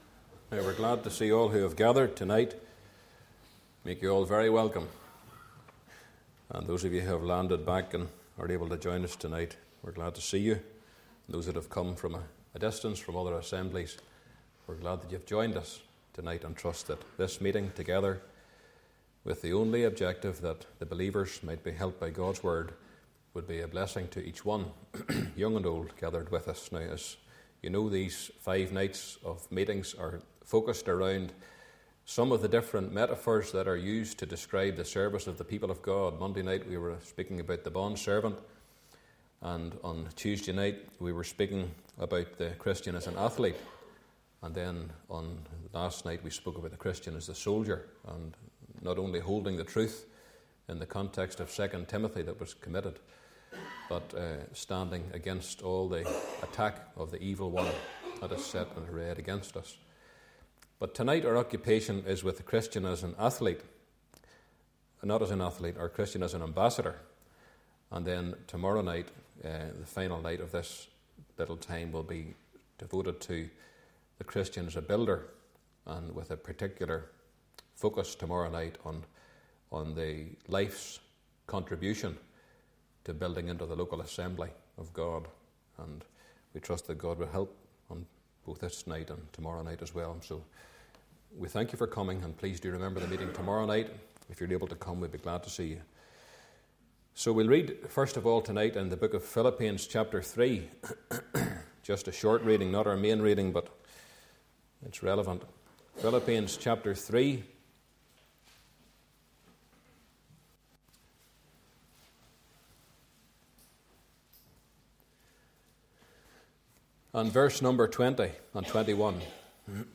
Meeting Type: Ministry